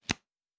pgs/Assets/Audio/Fantasy Interface Sounds/Cards Place 01.wav
Cards Place 01.wav